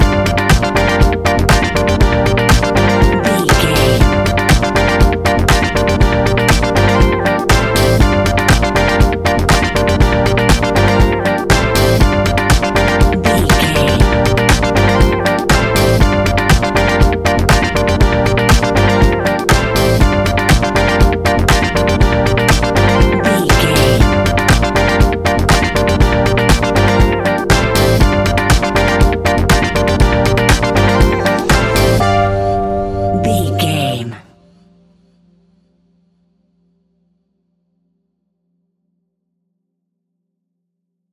Ionian/Major
laid back
Lounge
sparse
new age
chilled electronica
ambient
atmospheric